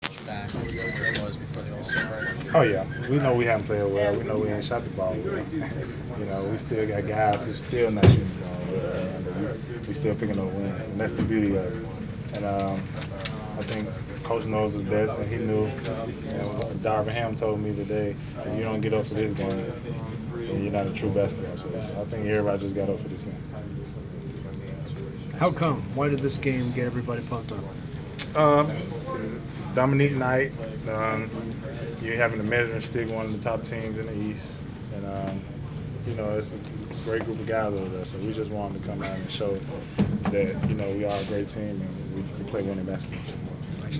Inside the Inquirer: Postgame presser with Atlanta Hawk DeMarre Carroll (3/6/15)
We attended the postgame presser of Atlanta Hawks’ forward DeMarre Carroll following his team’s 106-97 home win over the Cleveland Cavs on Mar. 6. Topics included the win and defending LeBron James.